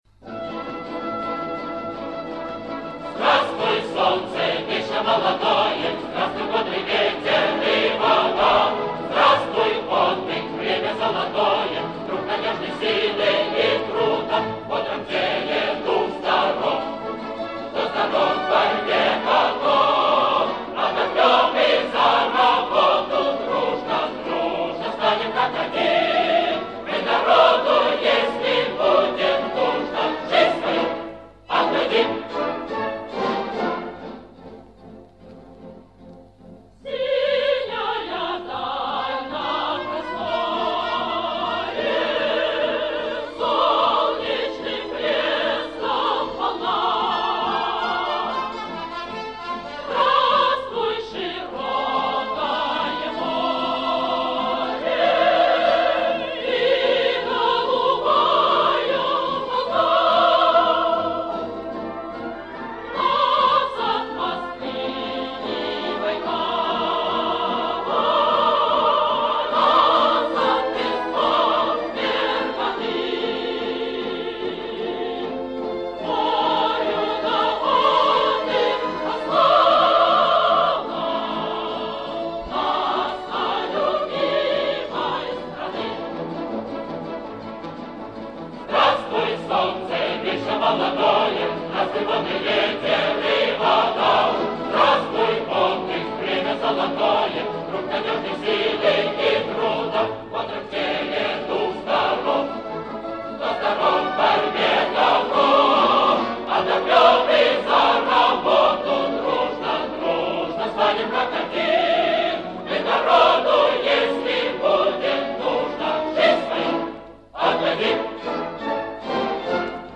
Послевоенное исполнение песни.
с пластинки